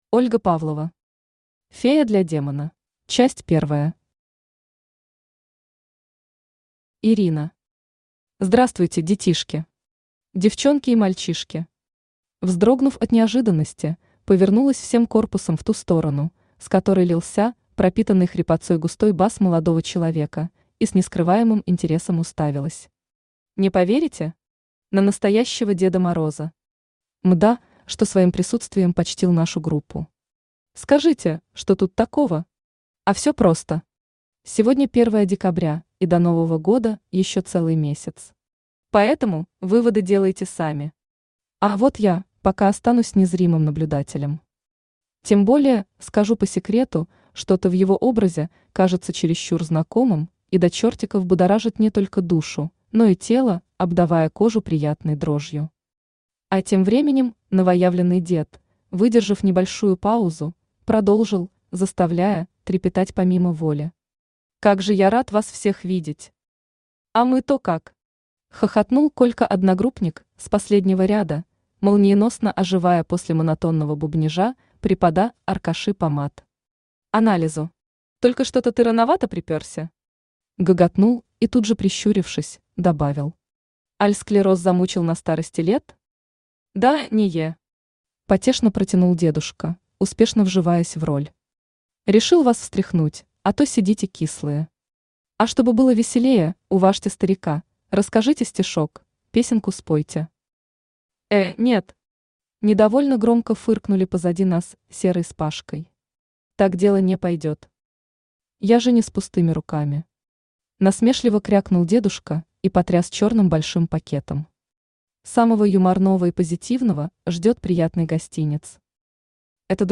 Aудиокнига Фея для демона Автор Ольга Анатольевна Павлова Читает аудиокнигу Авточтец ЛитРес.